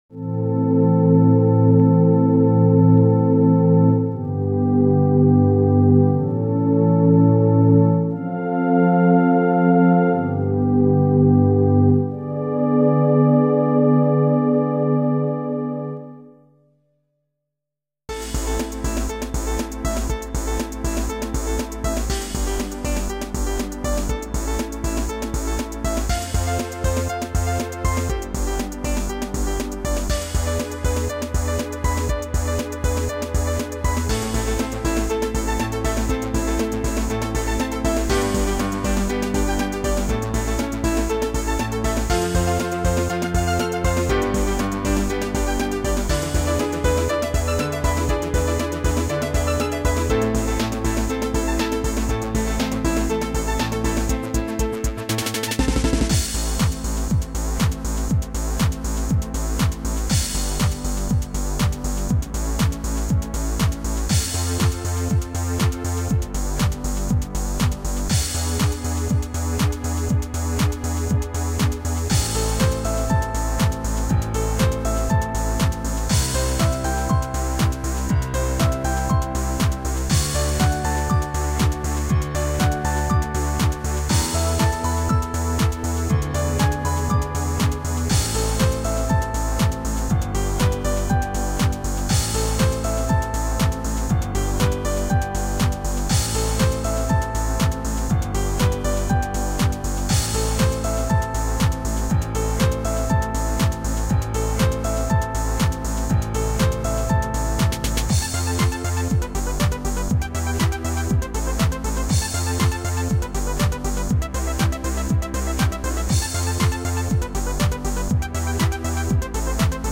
音楽作成ソフト MIXTURE を再インストールして 自分で音楽作ってみた
シロウト臭漂いまくりだけど